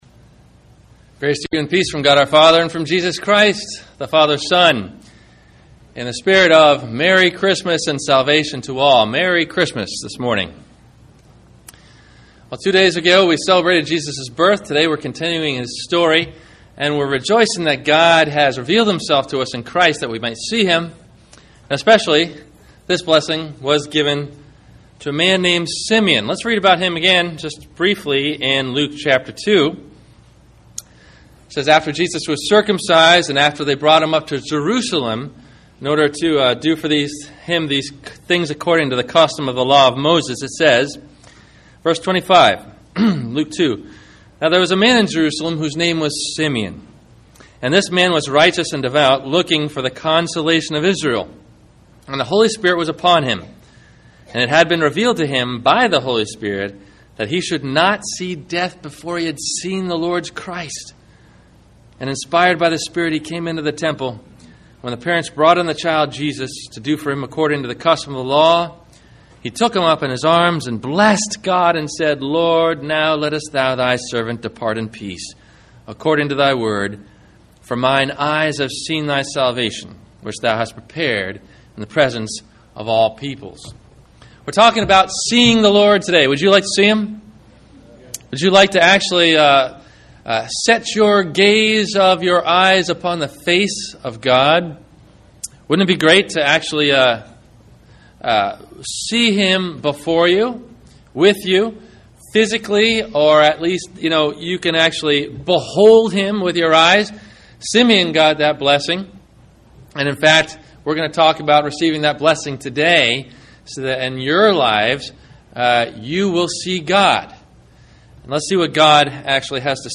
Mine eyes Have Seen Thy Salvation – Seeing God – Sermon – December 27 2009